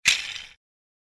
جلوه های صوتی
دانلود صدای ماشین 28 از ساعد نیوز با لینک مستقیم و کیفیت بالا
برچسب: دانلود آهنگ های افکت صوتی حمل و نقل دانلود آلبوم صدای انواع ماشین از افکت صوتی حمل و نقل